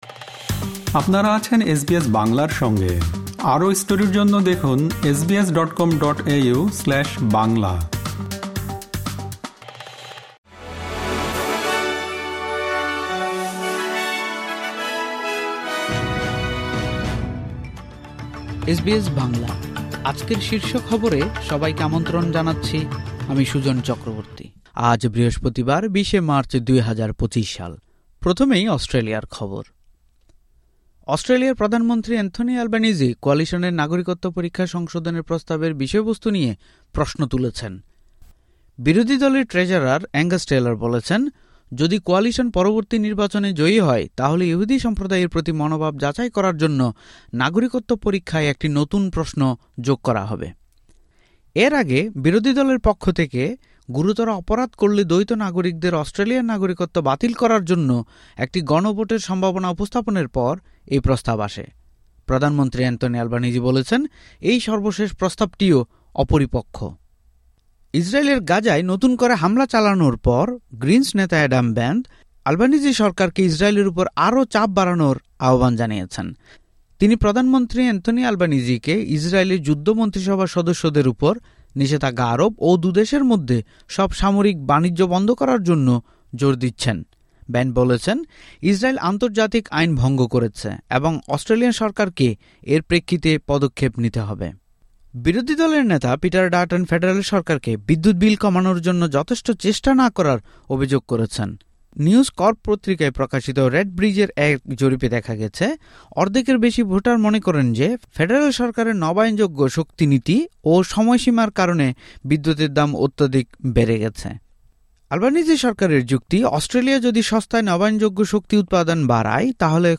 এসবিএস বাংলা শীর্ষ খবর: ২০ মার্চ, ২০২৫